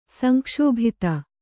♪ sankṣōbhita